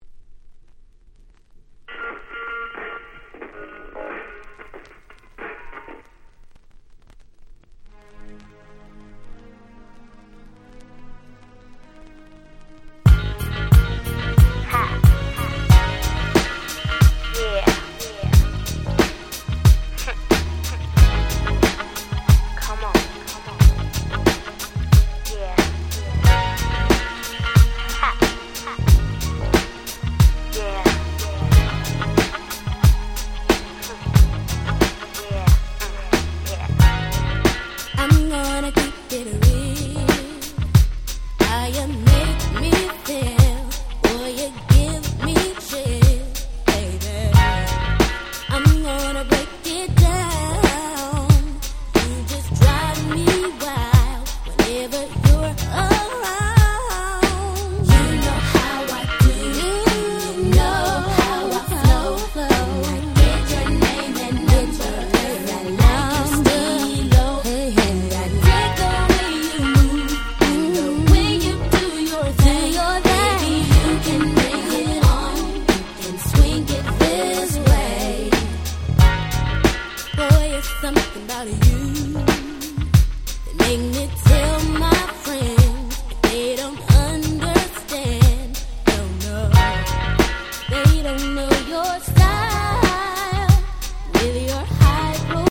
96' Smash Hit R&B !!
切ないメロディーの堪らない最高のHip Hop Soul !!